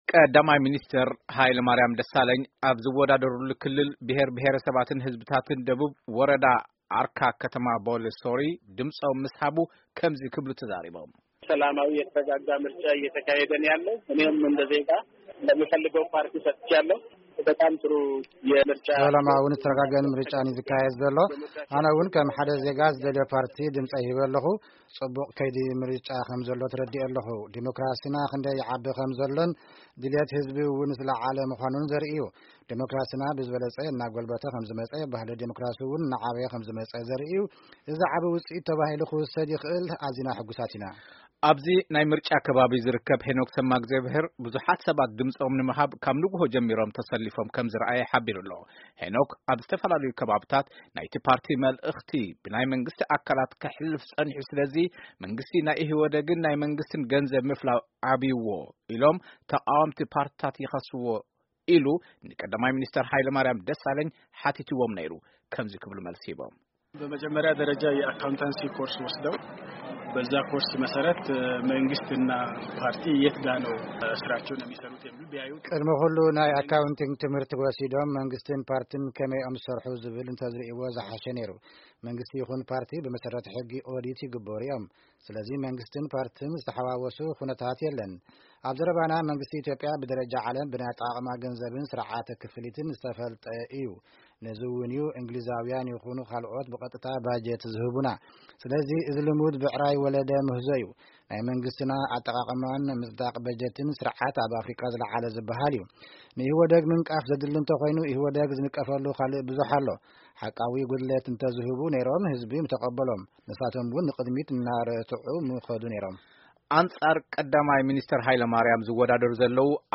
ሪፓርታዥ ሃገራዊ ምርጫ 2007 ኢትዮጵያ